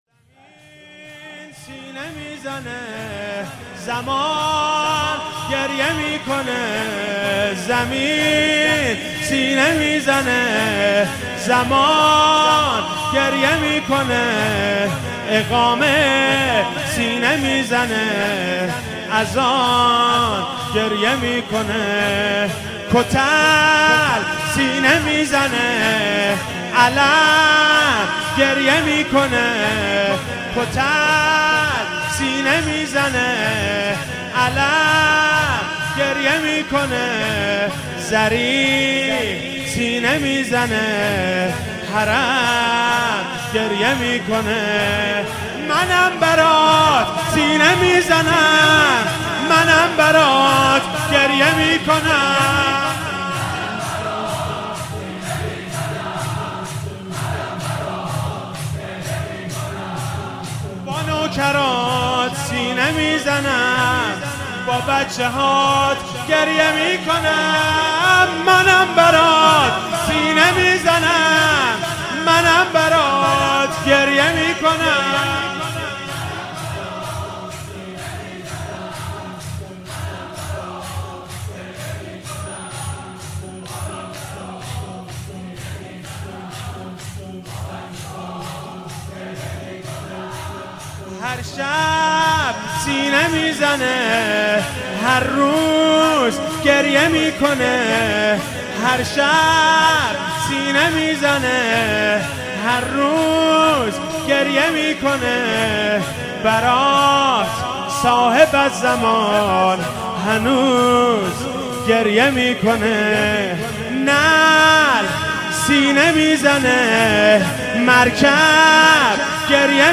فاطمیه 96 - شب دهم - شور - زمین سینه میزنه
فاطمیه